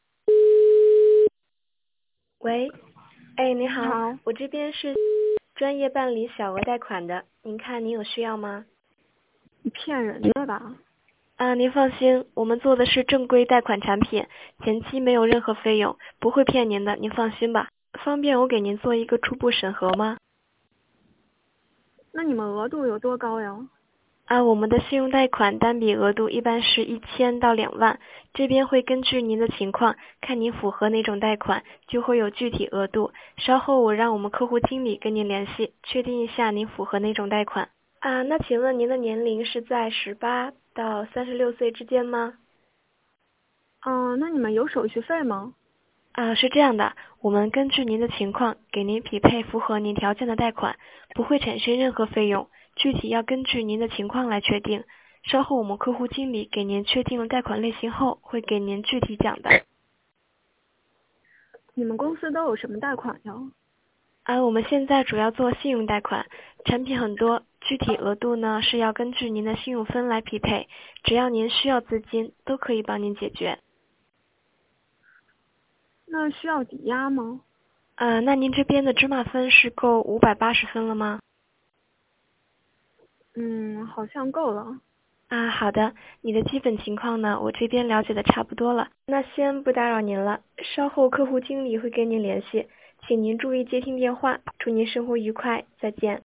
▲贷款推销案例